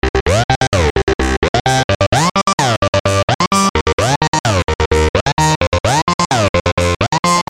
描述：电音风格的门控FX铅圈129 BPM，使用sytrus和良好的老骆驼空间完成。）
标签： 129 bpm Electro Loops Synth Loops 1.25 MB wav Key : Unknown
声道立体声